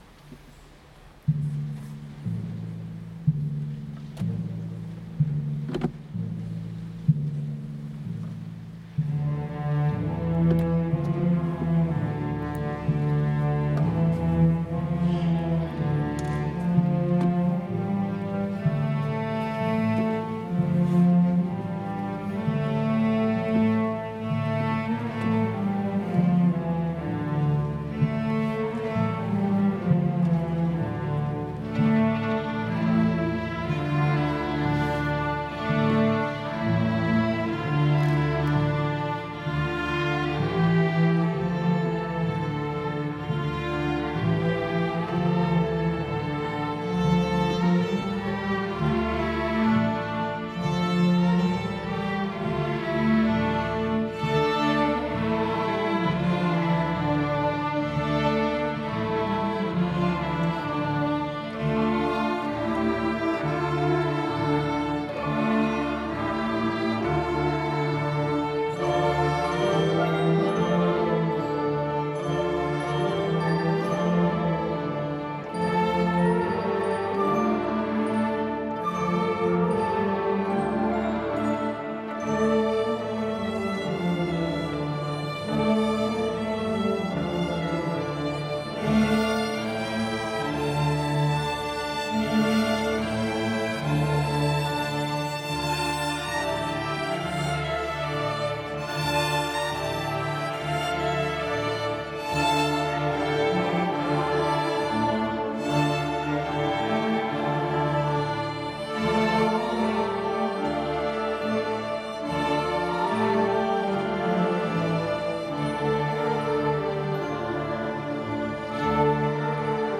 Mit wunderbaren Chorklängen, sanften Gitarren- und Bandsounds, atmosphärischer orchestraler Sinfonik und Orgelmusik von der Empore stimmten uns die großen musikalischen Ensembles am 11. Dezember in St. Gabriel auf Weihnachten 2024 ein.
Sinfonie Nr. 1, 3. Satz von Gustav Mahler, Sinfonieorchester Jgst. 5-12